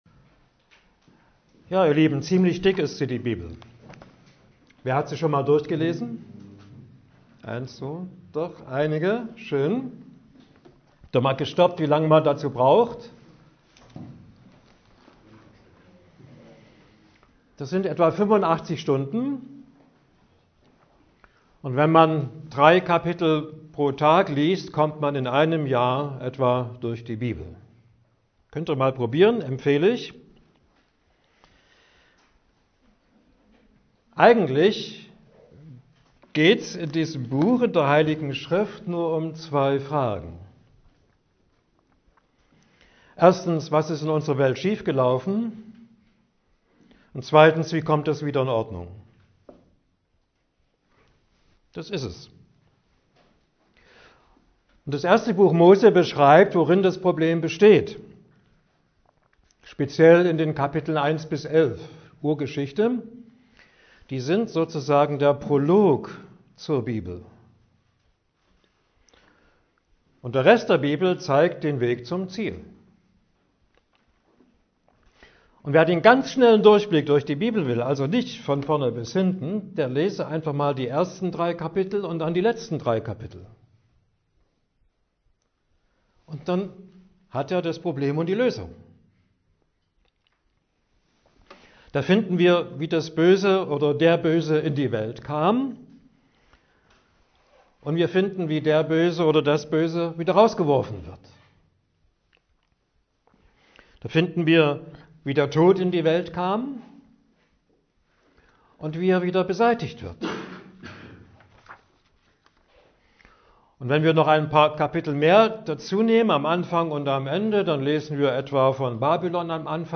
November 2024 Heruntergeladen 970 Mal Kategorie Audiodateien Vorträge Schlagwörter Genesis , Gott , Mensch , Bibel , Offenbarung , Gottes Herrschaft , Anfang , Ende , 1.